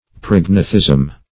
(pr[o^]g"n[.a]*th[i^]z'm or pr[o^]g*n[=a]"th[i^]z'm)